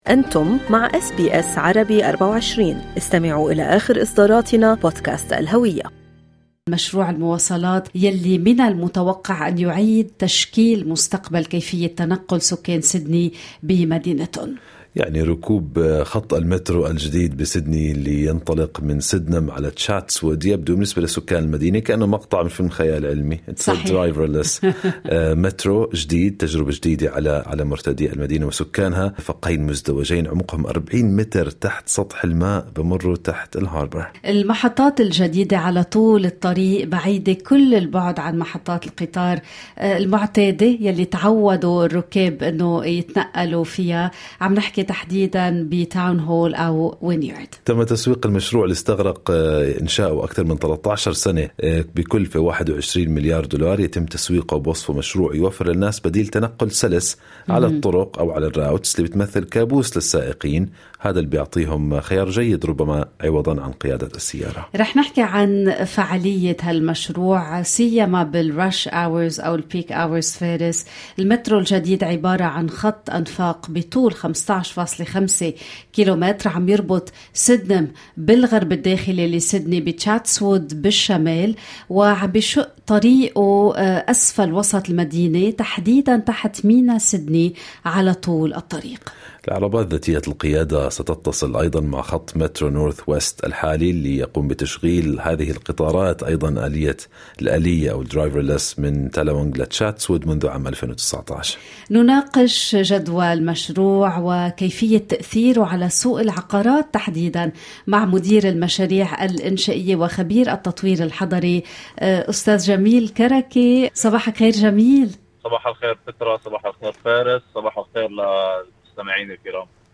Construction project manager